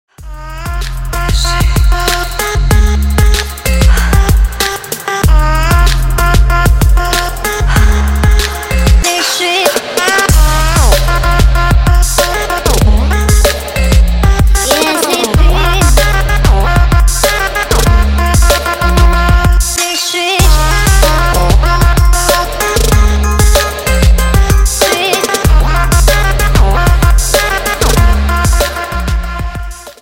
• Качество: 320 kbps, Stereo
Электроника
без слов